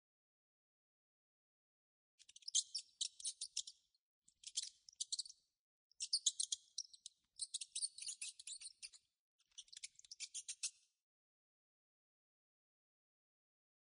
sfx_老鼠叫.ogg